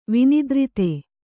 ♪ vinidrite